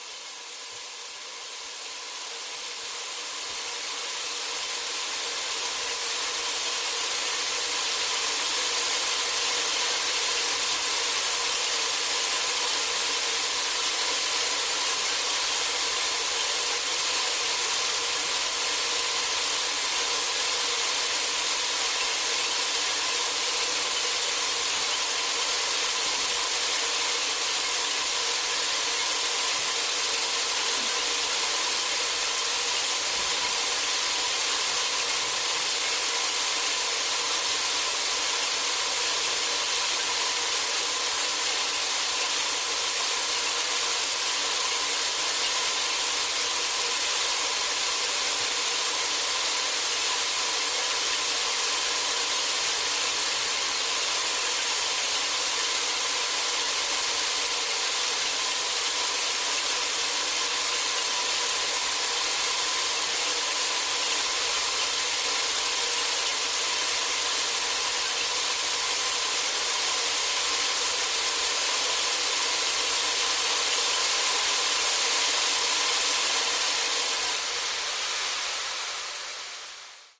Tato dešťová hůl, je zdrojem přírodních harmonizačních frekvencí, které už nedokážeme vědomě slyšet. Nástroj se vyrábí ručně a použité materiály jsou zcela přírodní.